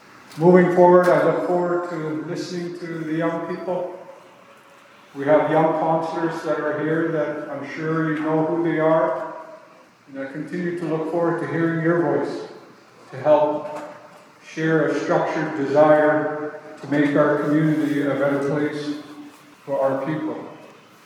Bearspaw First Nation held an Inauguration Ceremony for their elected Chief and Council
During a speech at the Inauguration, Chief Dixon expressed his thanks.